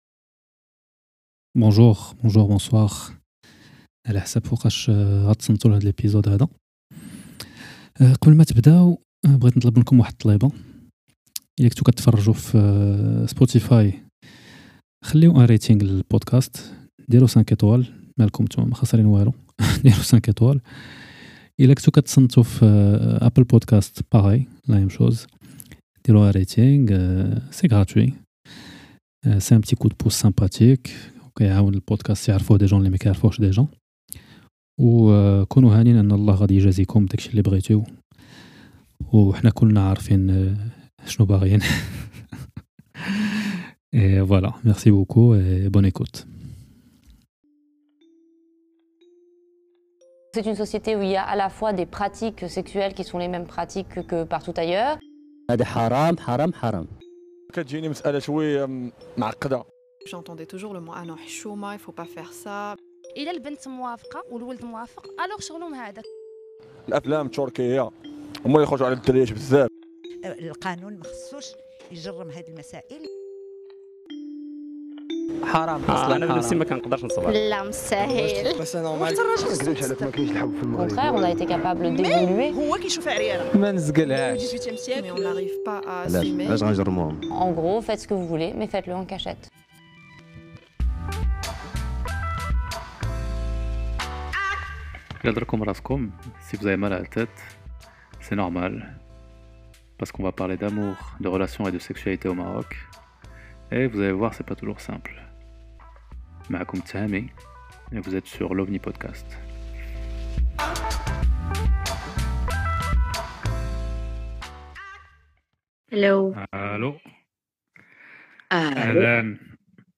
Je m'excuse pour la qualité du son dans certains de ces Lives, étant donné que l'enregistrement se fait sur téléphone (contrairement aux épisodes).